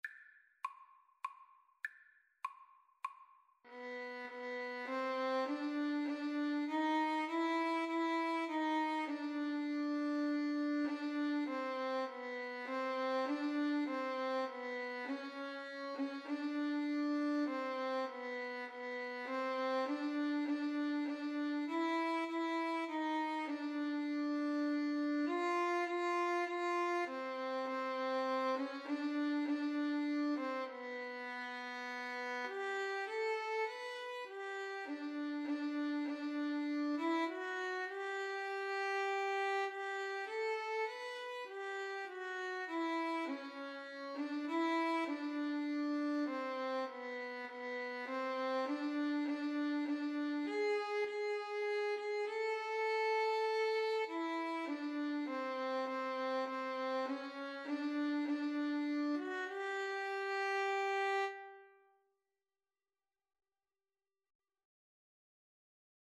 3/4 (View more 3/4 Music)
Classical (View more Classical Violin Duet Music)